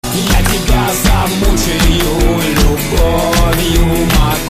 • Качество: 128, Stereo
мужской вокал
веселые
романтичные